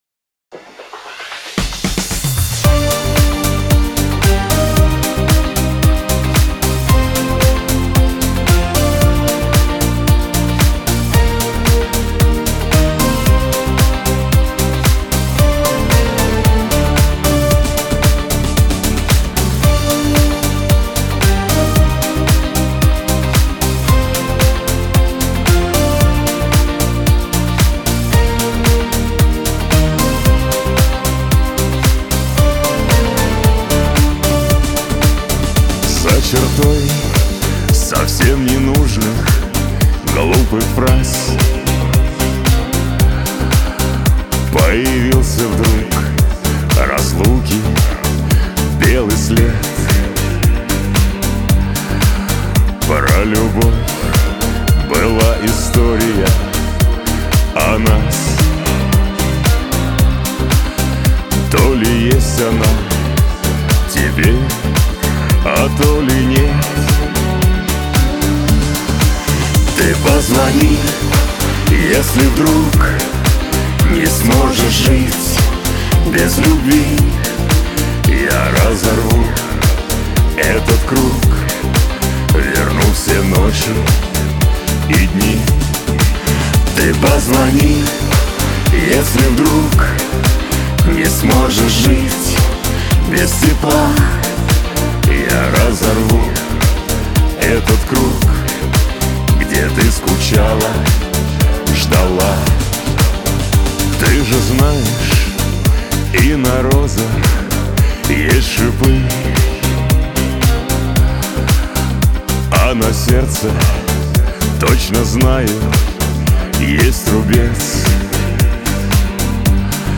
это эмоциональная песня в жанре поп